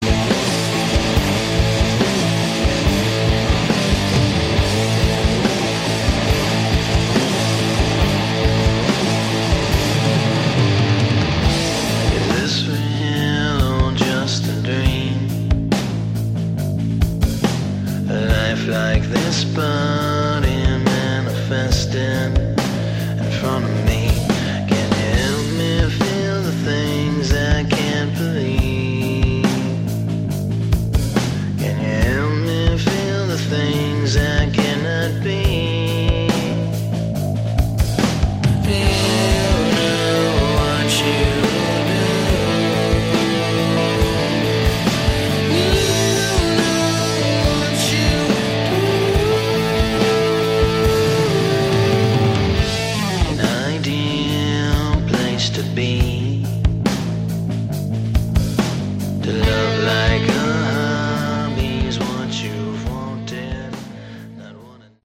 Category: Hard Rock
lead vocals, bass
guitars, keyboards, backing vocals
drums